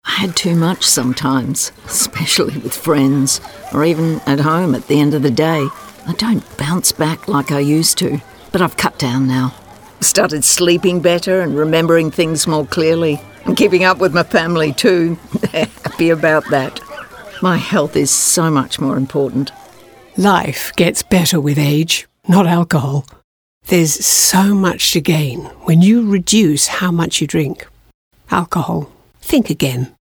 Please note: this campaign features paid actors with timebound talent agreements.
Radio commercials